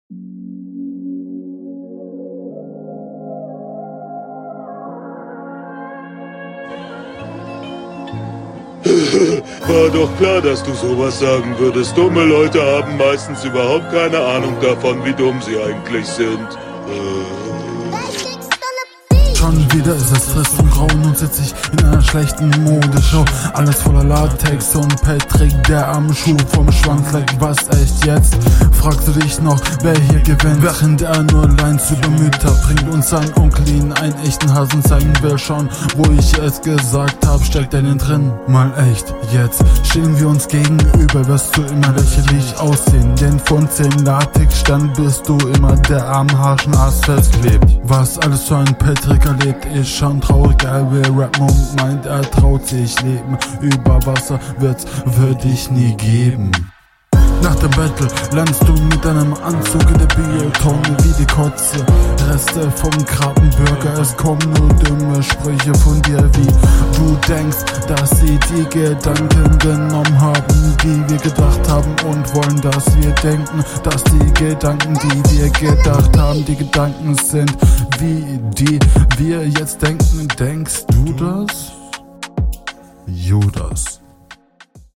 Du bist flowlich etwas besser drauf als in der RR.